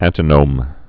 (ăntə-nōm)